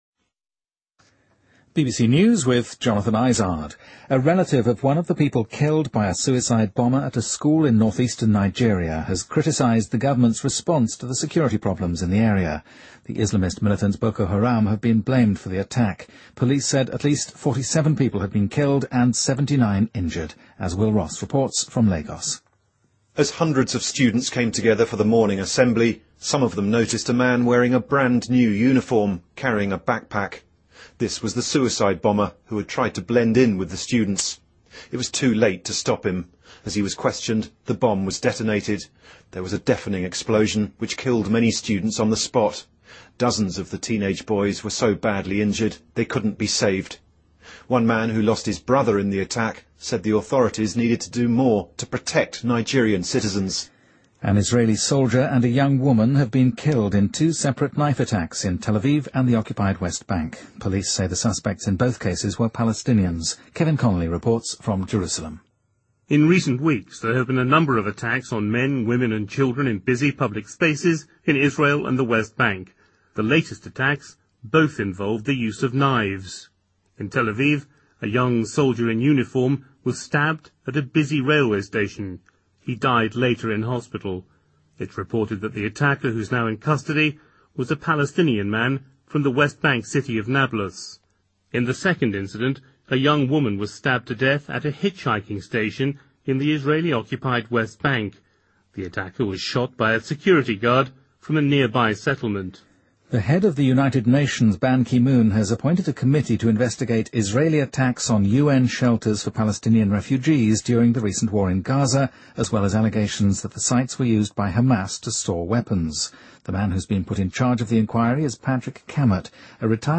BBC news,意大利上诉法院宣布五年前未能成功预测强地震的七名科学家无罪